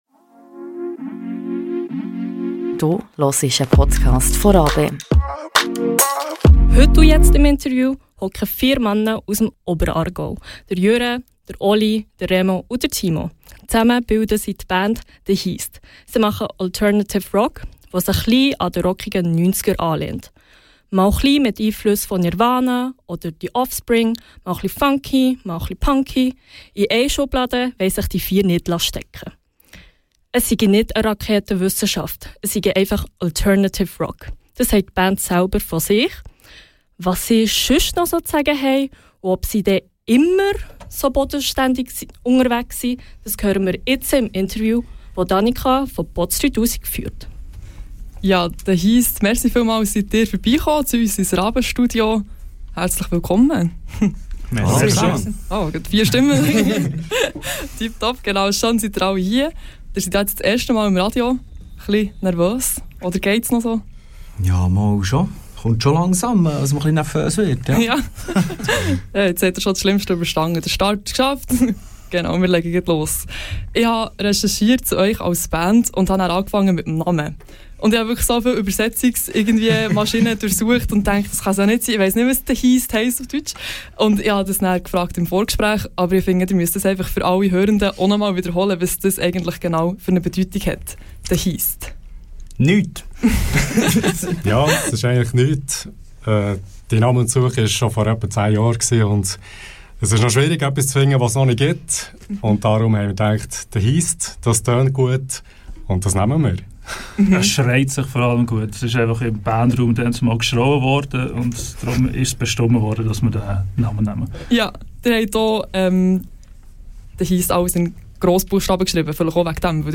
Die Band "THE HEAST" im Interview bei Botz3000 ~ Radio RaBe Podcast